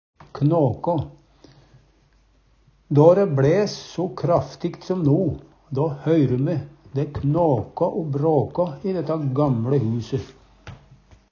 knåkå - Numedalsmål (en-US)